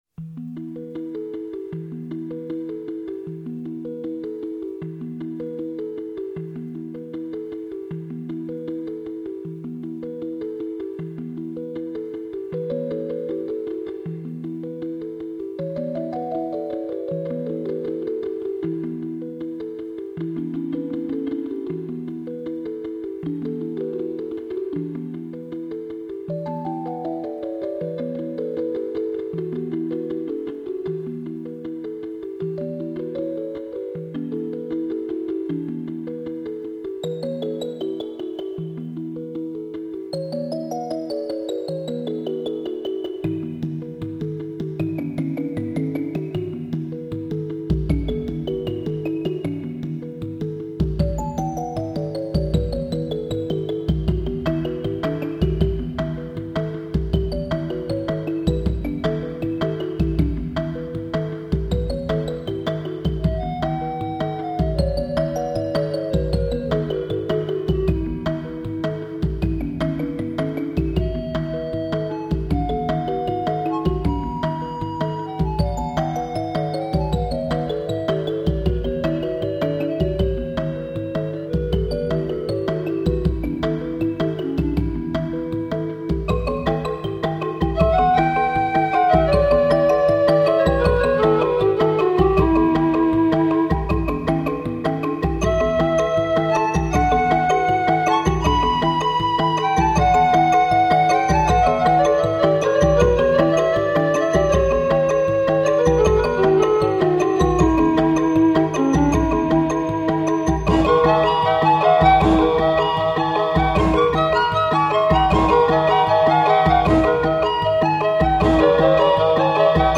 All sounds must be produced by one or more ESQ1 and/or SQ80.
Song of Mbira All ESQ-1 with an external reverb.